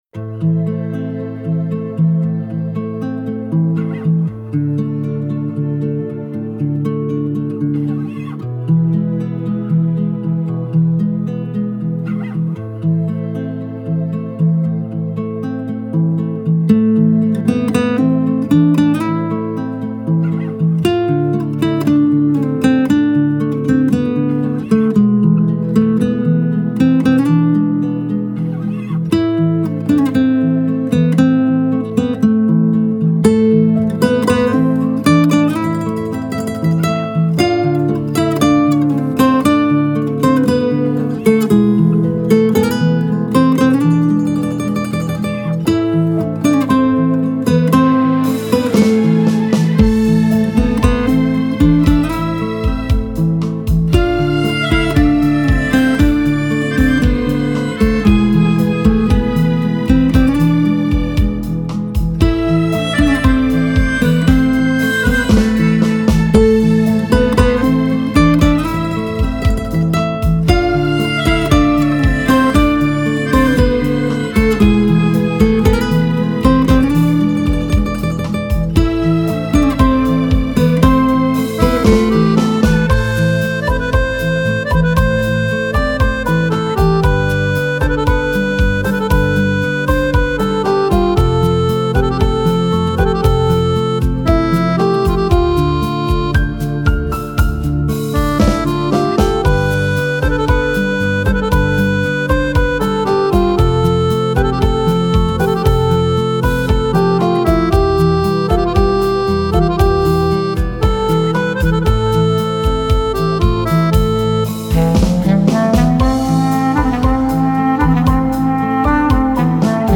پیانو ، ملودیکا ، گیتار